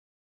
silentloop.au